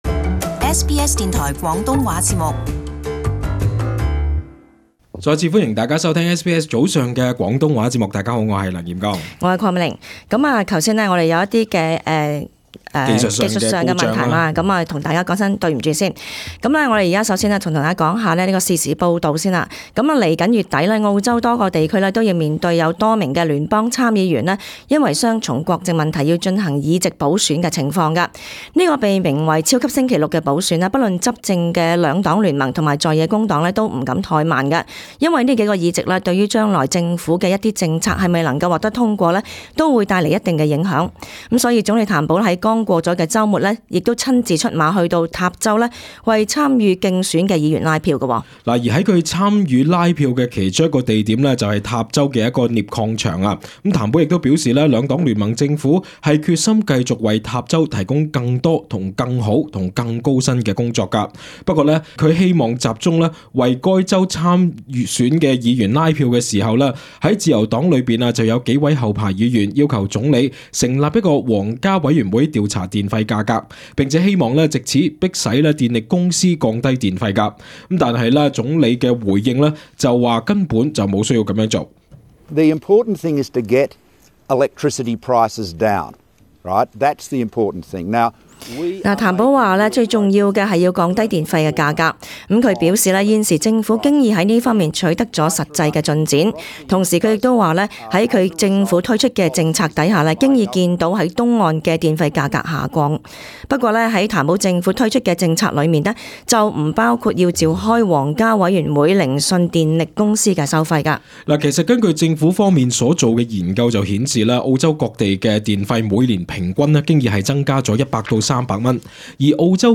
【時事報導】譚保拒絕設皇家委員會調查電費